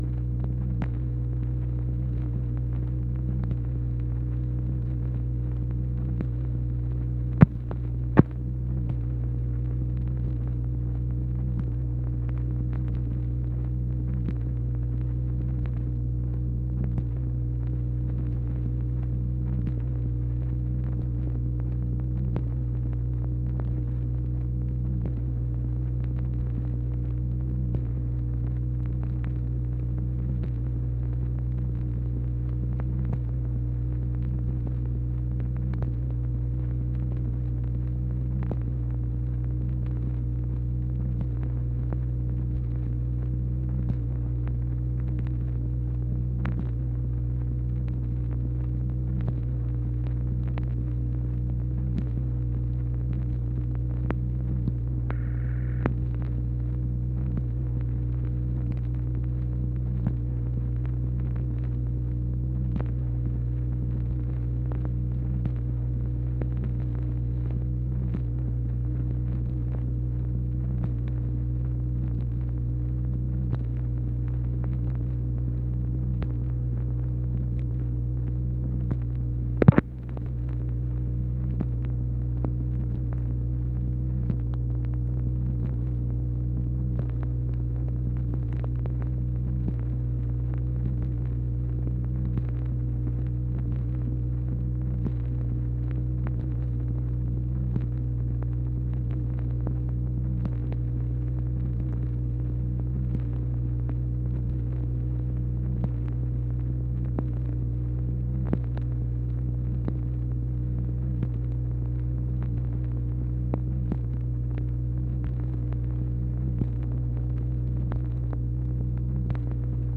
MACHINE NOISE, January 1, 1964
Secret White House Tapes | Lyndon B. Johnson Presidency